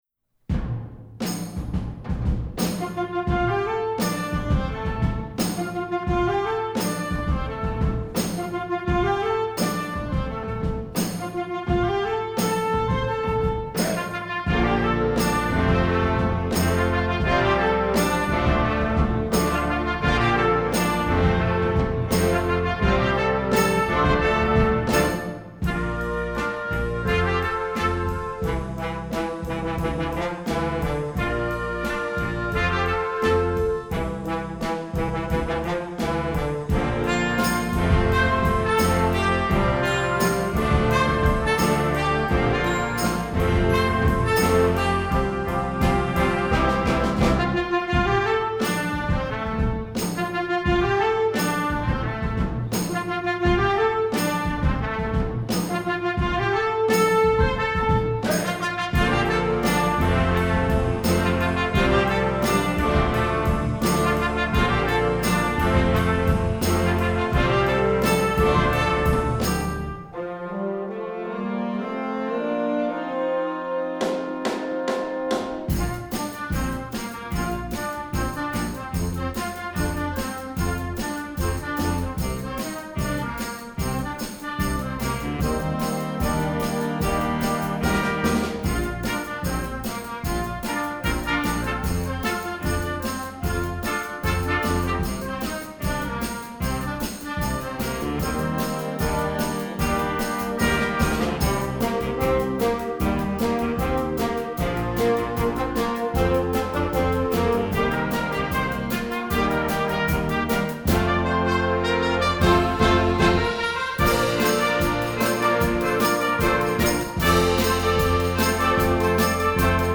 Instrumental Concert Band Popular
Concert Band